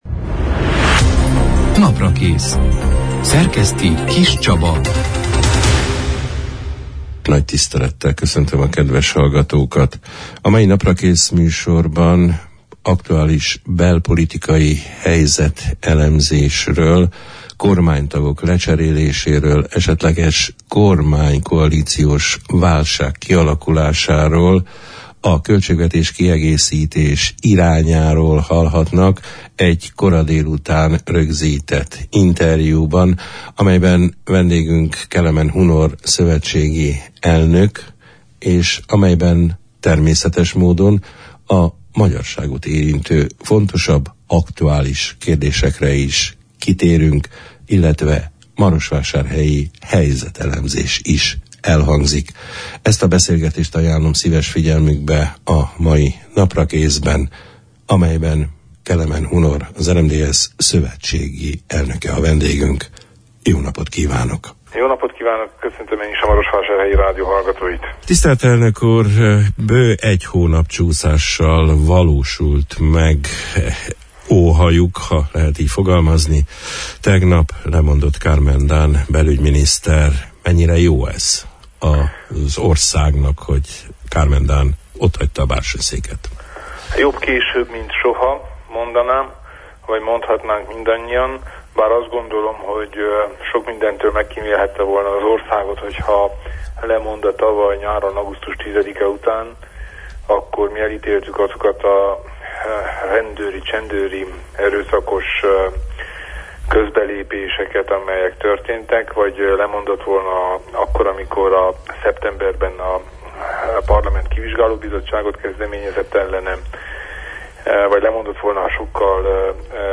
A két miniszter leváltásával kapcsolatos aktualitásokról, egy esetleges koalíciós válság kibontakozásáról, költségvetés – kiigazítási lehetőségekről, a magyarságot érintő kényes kérdésekről, anyanyelvhasználati visszalépésről, az úzvölgyi temető helyzetéről, a marosvásárhelyi iskolaügyről, a helyi RMDSZ szervezetek közötti elmérgesedett viszonyról beszélgettünk a július 16 – án, kedden délután elhangzott Naprakész műsorban, egy kora délután rögzített interjúban, Kelemen Hunorral, az RMDSZ szövetségi elnökével.